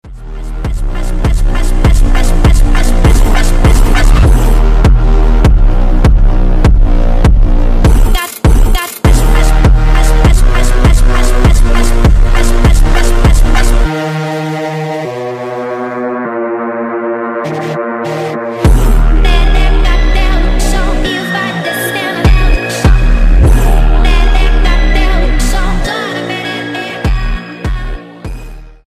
Клубные Рингтоны
Рингтоны Электроника